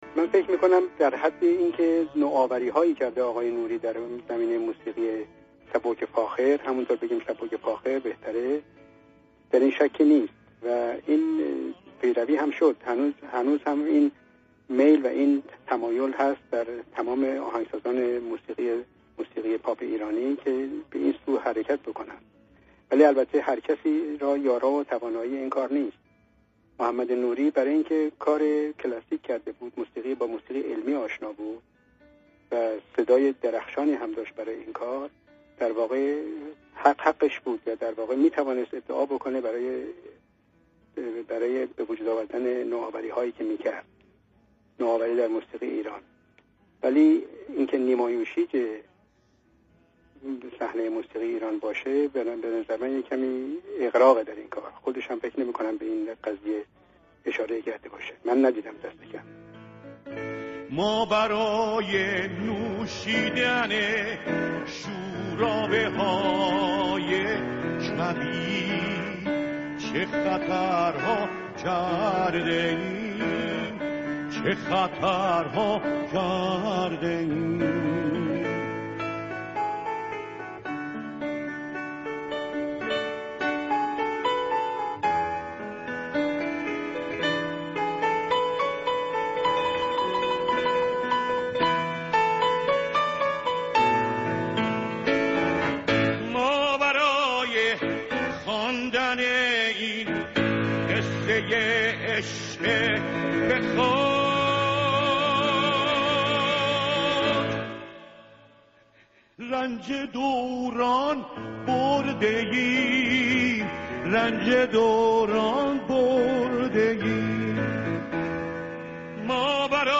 Radio Zamaneh Interview
The following words have been removed from the sentences in Part 4 and replaced with  a pluck: